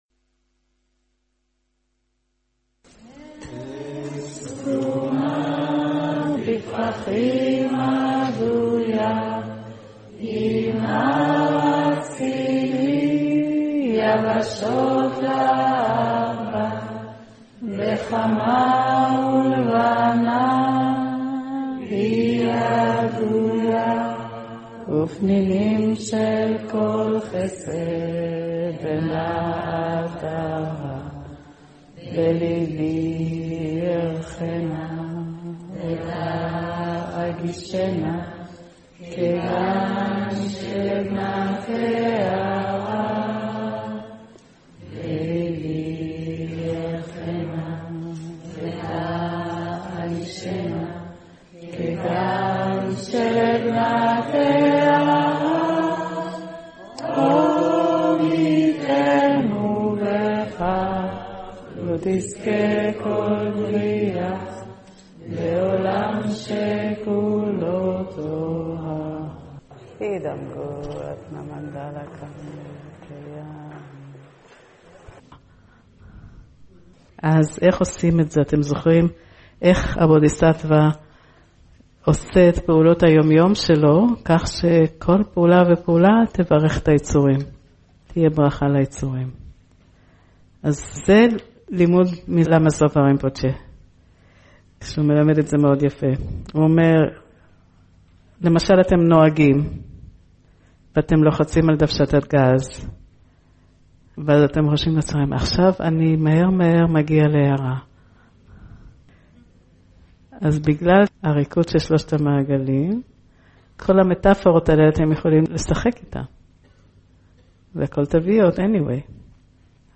הקלטות מקורס
במרכז רוחני ערבה